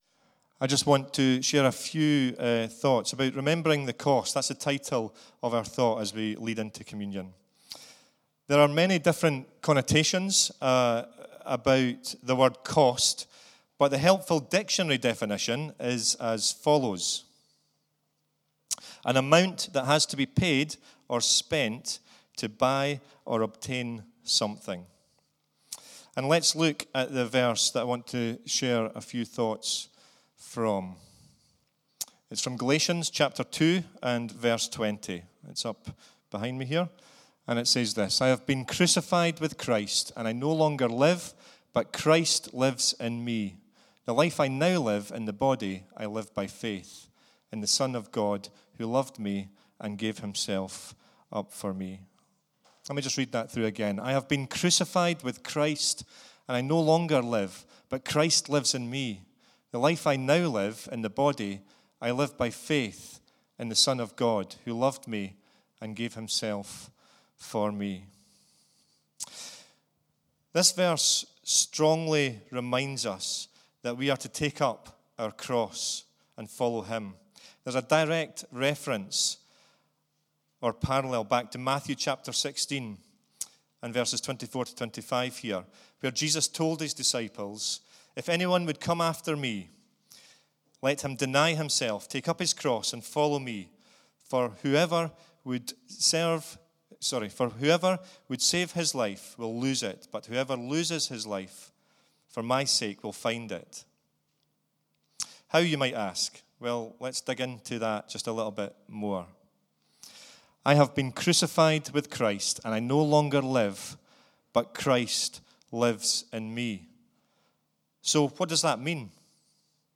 Talks from the Communion services during 2019